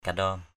/ka-ɗɔ:m/ (d. đg.) bụm = joindre les mains en forme de coupe. brah sa kandaom bH s% k_Q> một bụm gạo. kandaom haluk klak k_Q> hl~K k*K bụm đất bỏ.